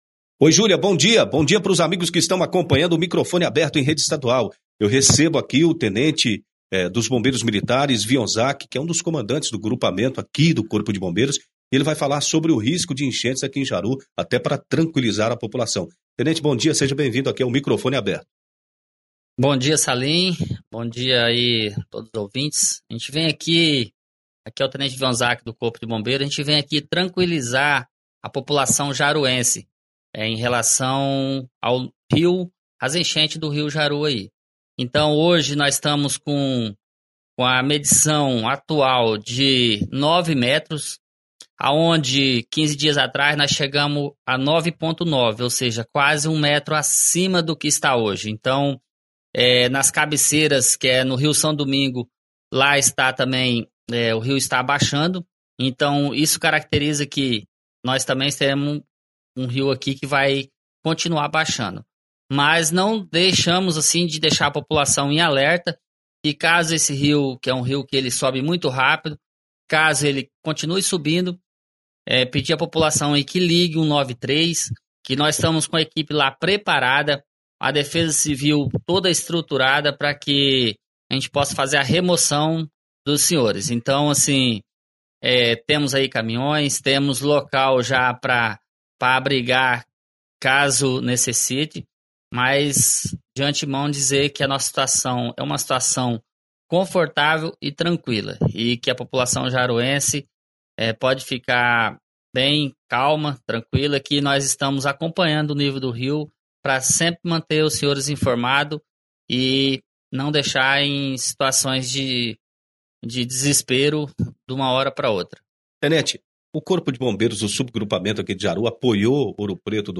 A afirmação foi feita durante entrevista ao programa Microfone Aberto